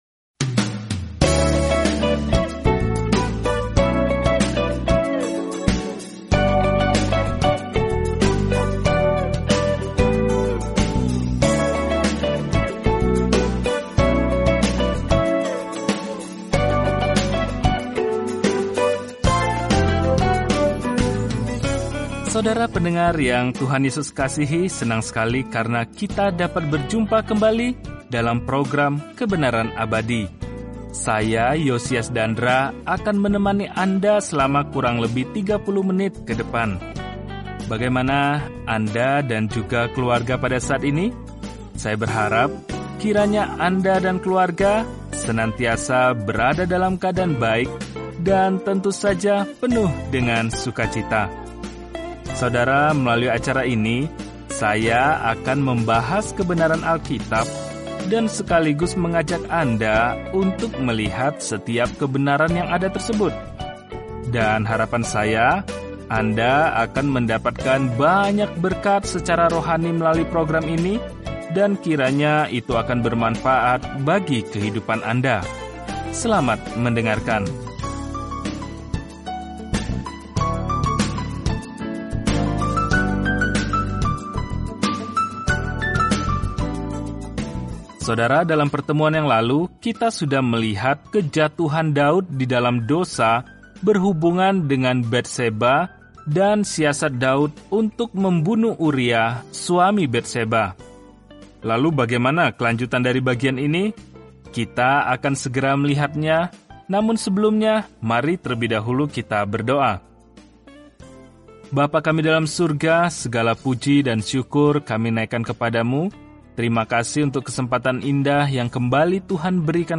Telusuri 2 Samuel setiap hari sambil mendengarkan pelajaran audio dan membaca ayat-ayat tertentu dari firman Tuhan.